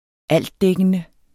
Udtale [ -ˌdεgənə ]